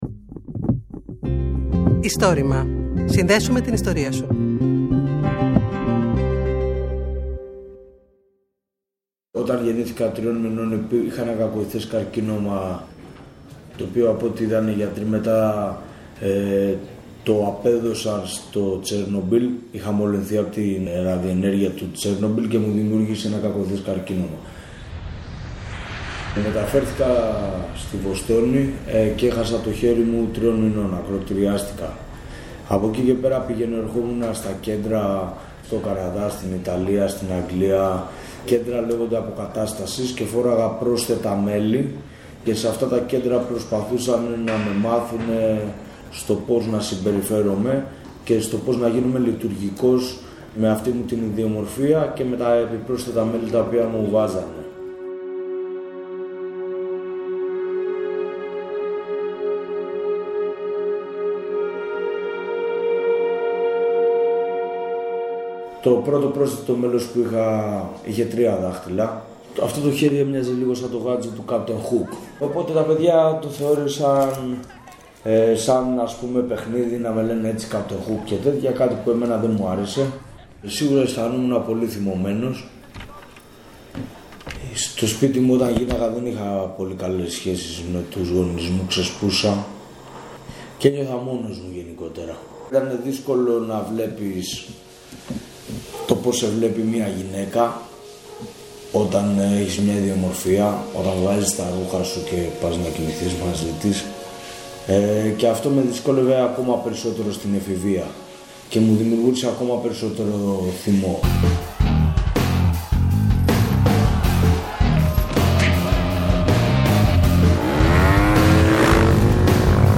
Μουσική: William Ryan Fritch